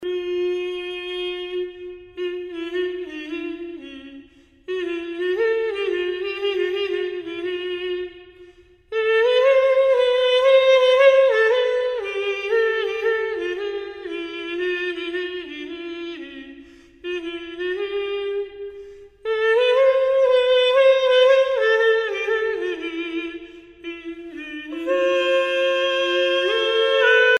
Musique médiévale
Pièce musicale éditée